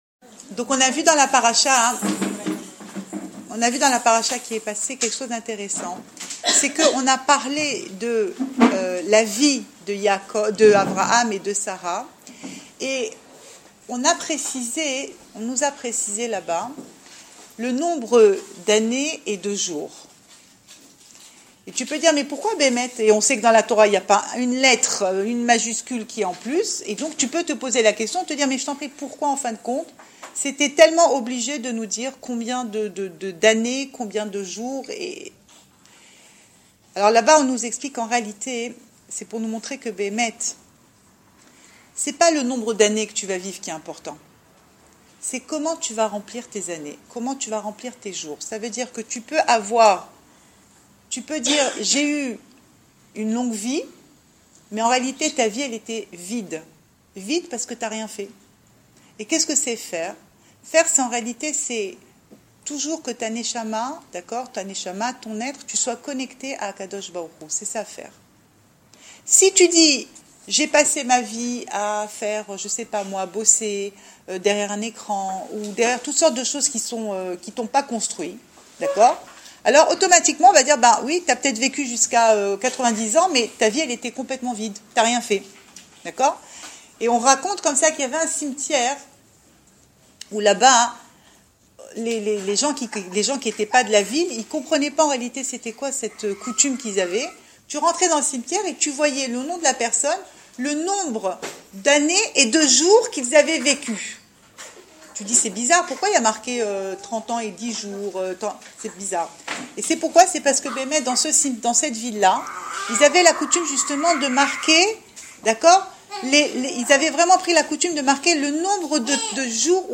Cours audio Emouna Le coin des femmes - 10 novembre 2015 29 décembre 2015 Et toi, combien de jours as-tu vécu ? Enregistré à Raanana